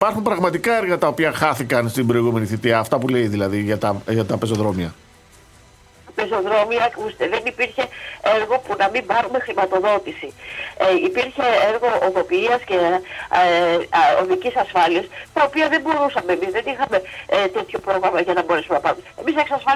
Ακολουθεί το ηχητικό απόσπασμα της συνέντευξης της κας. Θωμαΐδου, με το οποίο συνόδευσε τη δήλωση του ο Δήμαρχος Νέας Ιωνίας: